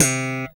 Index of /90_sSampleCDs/Roland - Rhythm Section/BS _E.Bass 1/BS _5str v_s